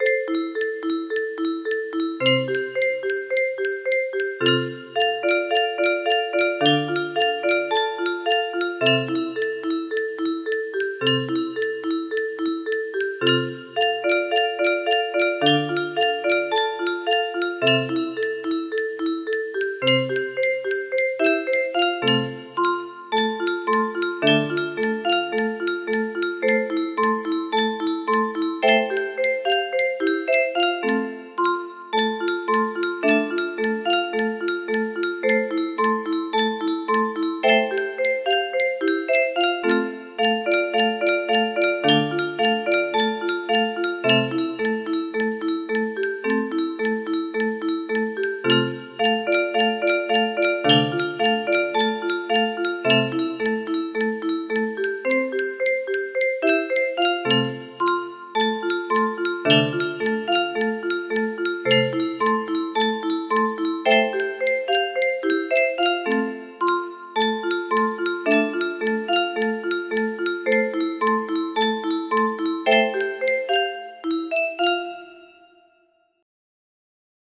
with an arrangement for 30 notes